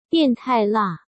biàn tài là